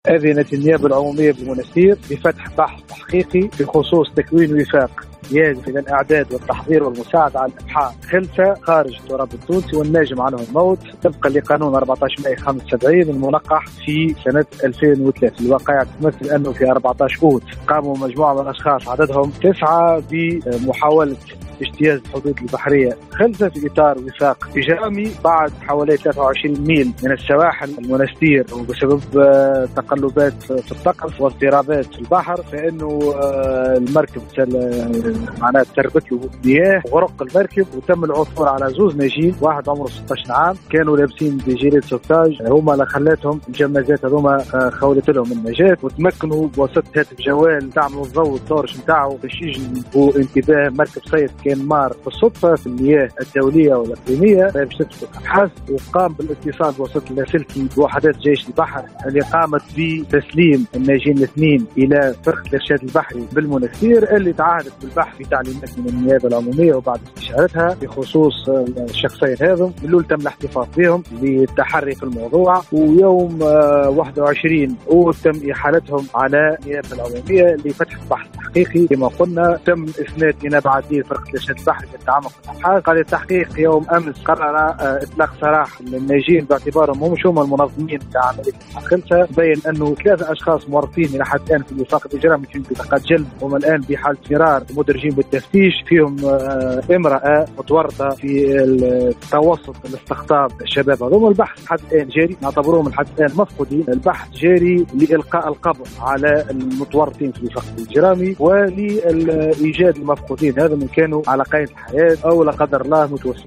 أكد مساعد الوكيل العام والناطق الرسمي باسم محاكم المنستير والمهدية فريد بن جحا في تصريح ل”ام اف ام” اليوم ان النيابة العمومية بالمنستير اذنت بفتح بحث تحقيقي بخصوص تكوين وفاق قصد اجتياز الحدود البحرية بطريقة غير نظامية و ذلك في حادث غرق مركب هجرة غير نظامية قبالة سواحل المنستير.